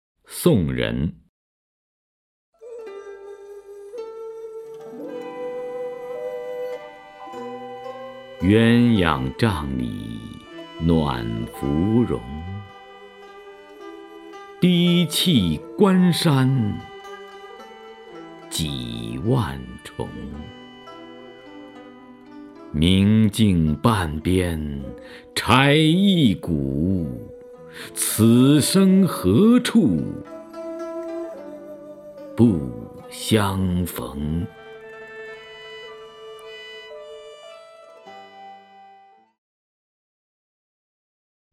徐涛朗诵：《送人》(（唐）杜牧) （唐）杜牧 名家朗诵欣赏徐涛 语文PLUS